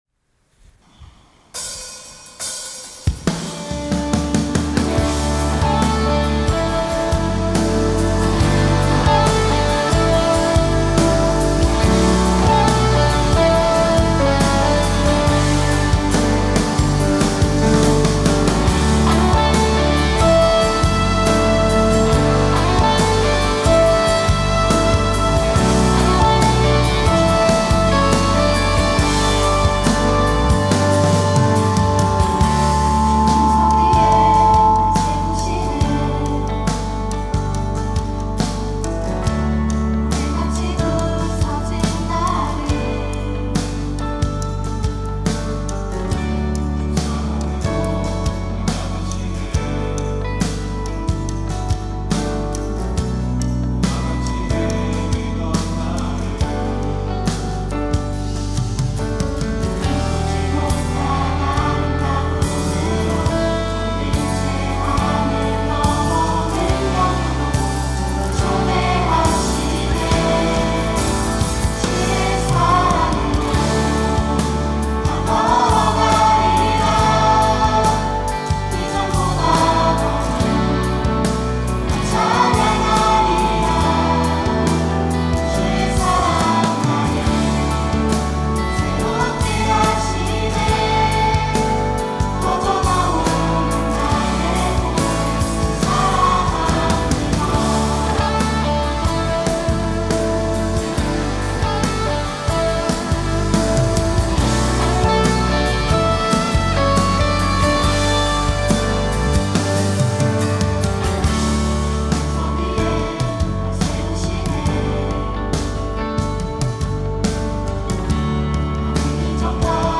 특송과 특주 - 주의 사랑으로